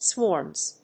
発音記号
• / swɔrmz(米国英語)
• / swɔ:rmz(英国英語)
swarms.mp3